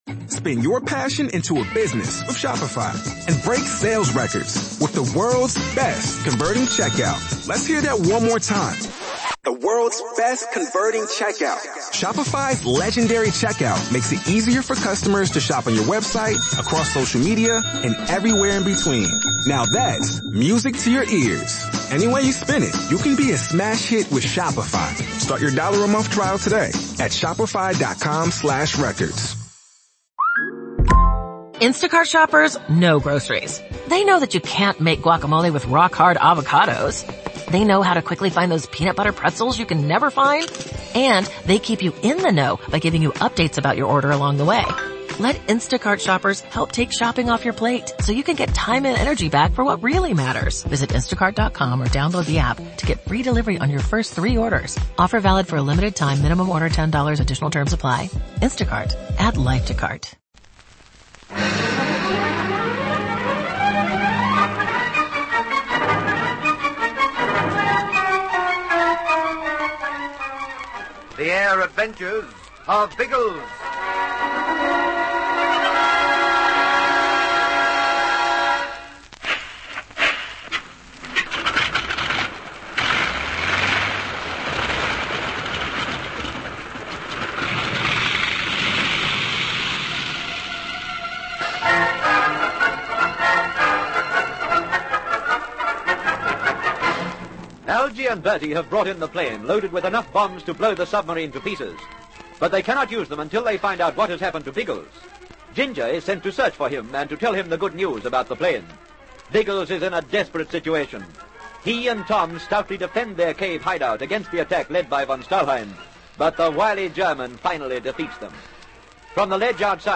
The Air Adventures of Biggles was a popular radio show that ran for almost a decade in Australia, from 1945 to 1954. It was based on the children's adventure novels of the same name by W.E. Johns, which chronicled the exploits of Major James Bigglesworth, a World War I flying ace who continued to have thrilling adventures in the years that followed.